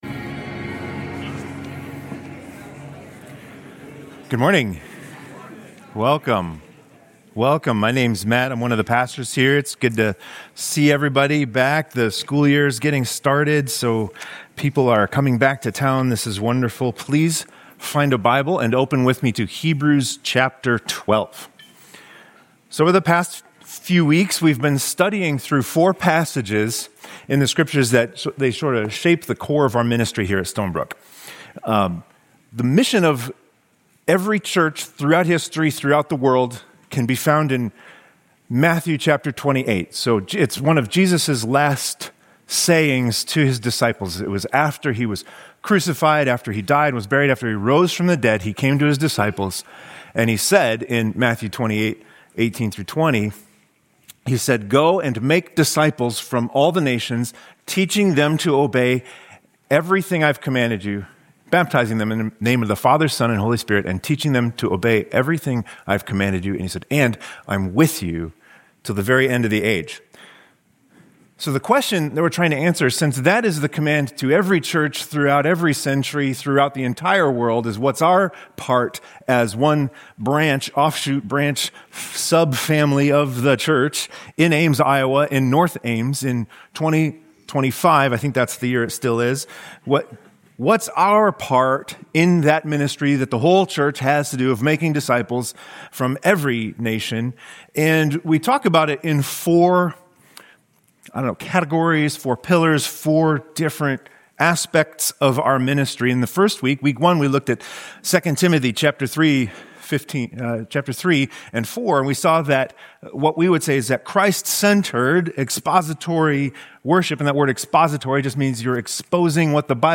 Sunday morning worship is the hub and starting point of our week of walking with the Lord.